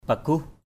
paguh.mp3